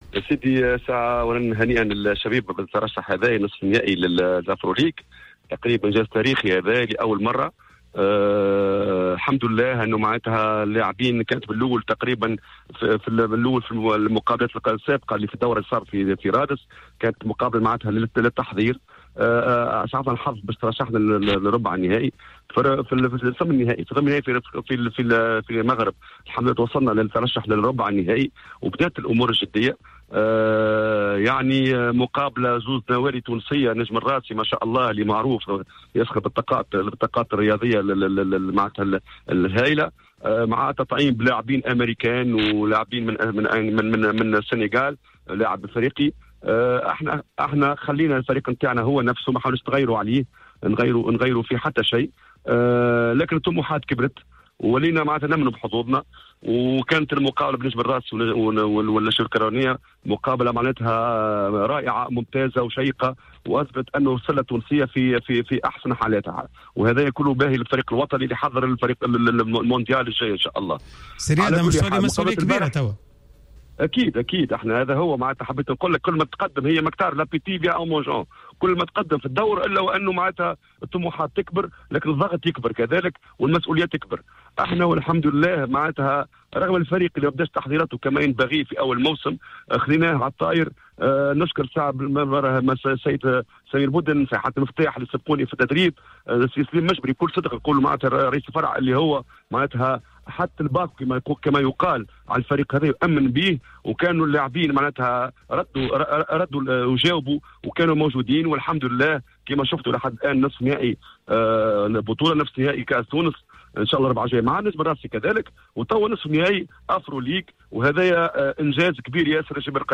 مداخلة في حصة "راديو سبور"